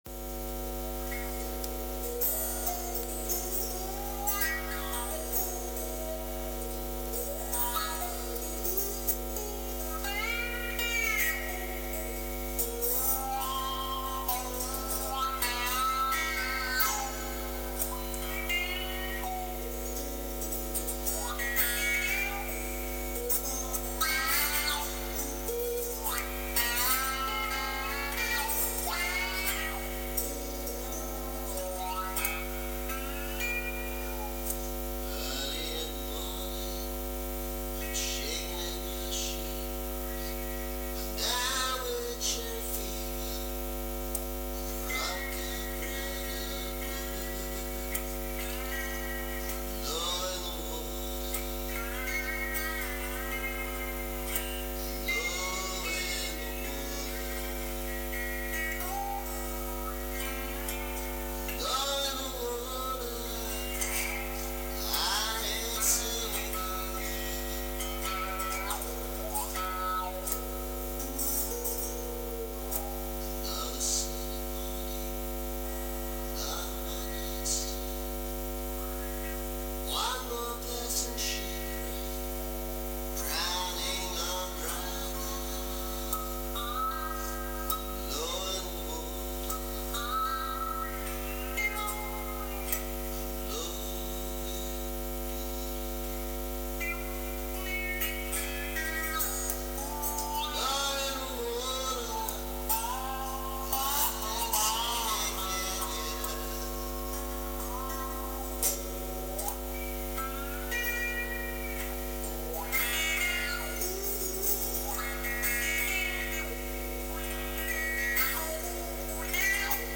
Here’s a much older electric version, recorded on much cheaper equipment…
emastered_low-in-the-water-electric-1.mp3